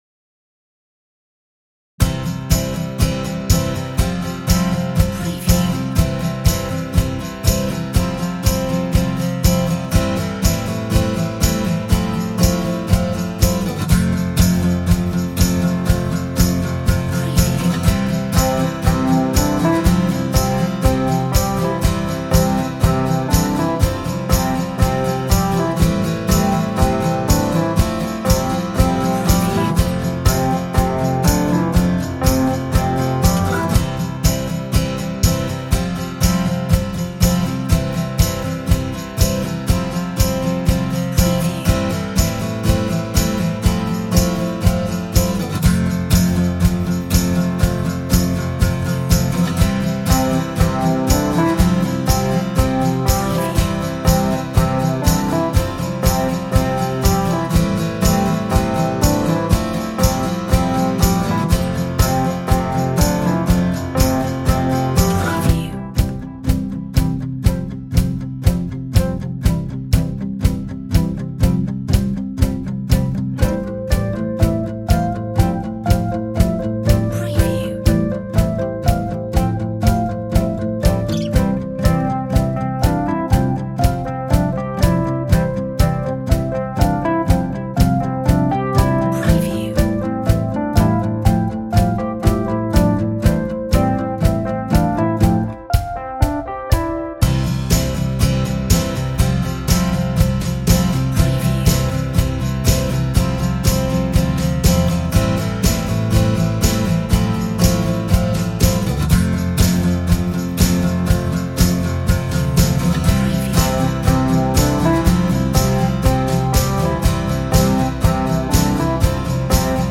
Bubbly folk style